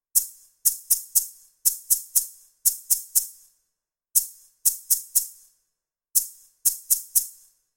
maracas.mp3